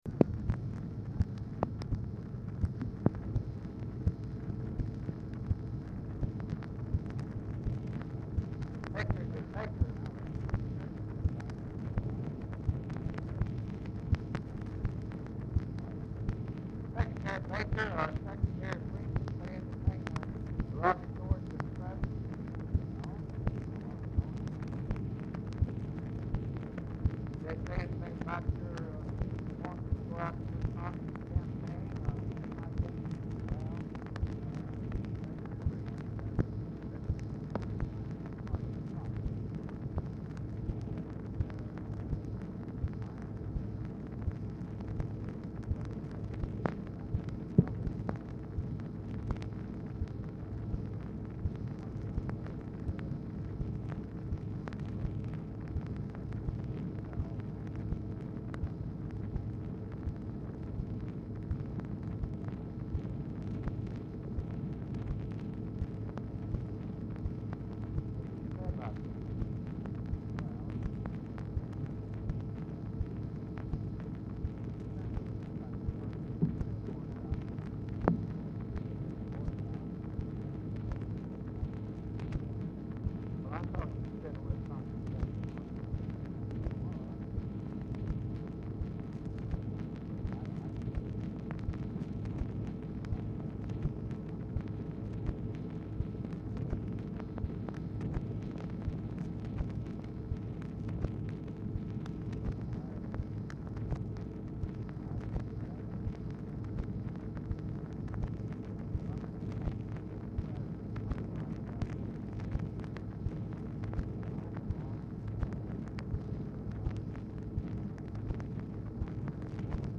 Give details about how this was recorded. POOR SOUND QUALITY; CONVERSATION IS ALMOST COMPLETELY INAUDIBLE Dictation belt